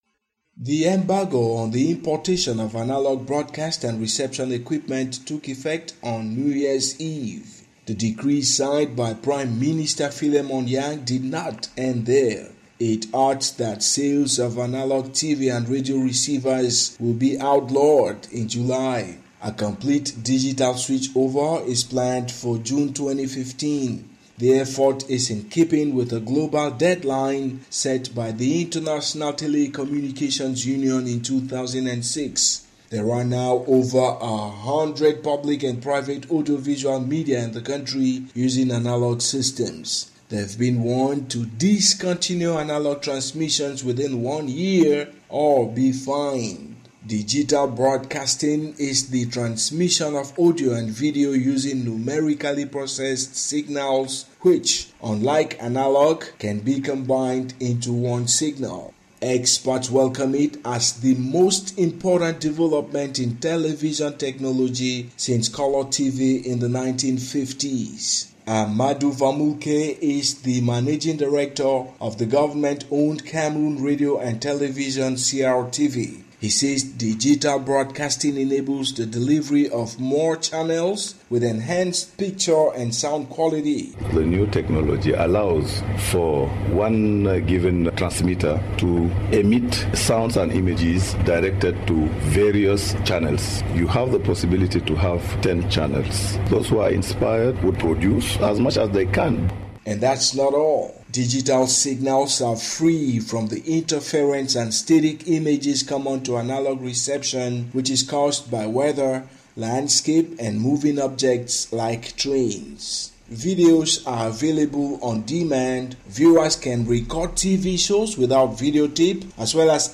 Listen to report on digital broadcasting in Cameroon